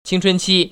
[qīngchūnqī] 칭춘치